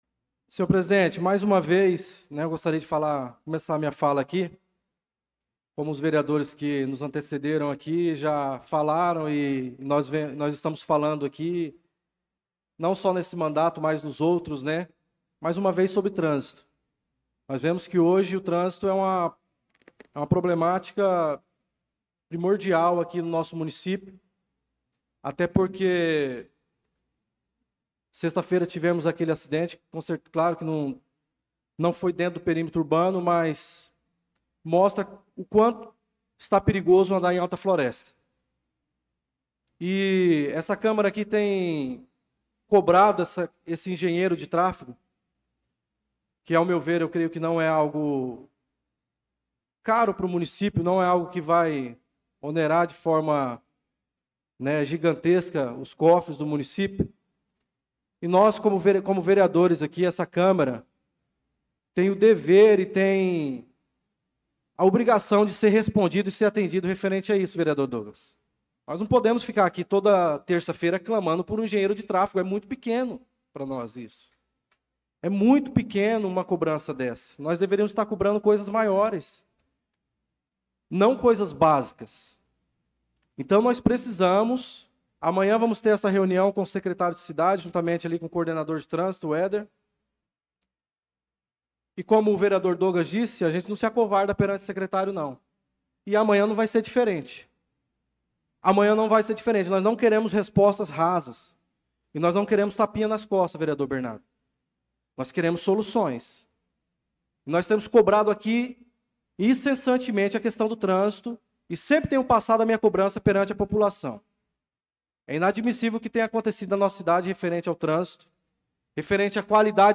Pronunciamento do vereador Darlan Carvalho na Sessão Ordinária do dia 25/08/2025.